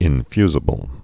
(ĭn-fyzə-bəl)